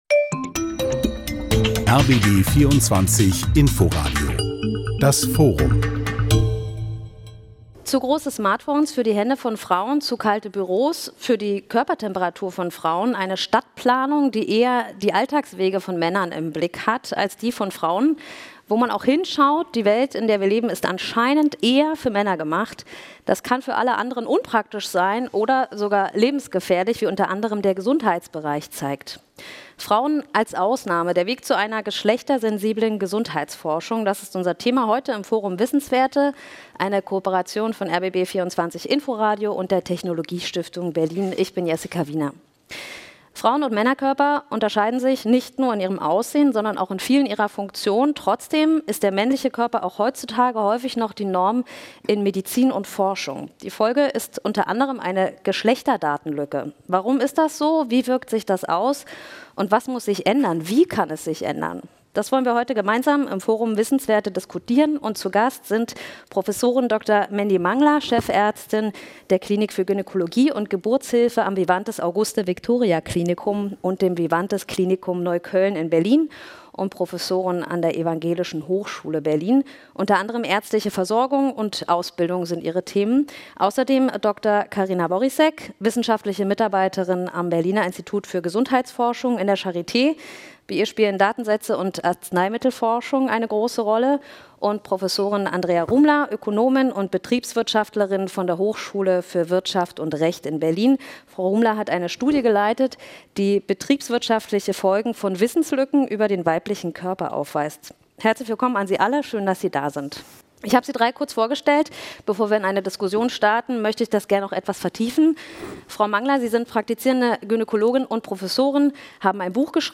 Forum - Frauen als Ausnahme?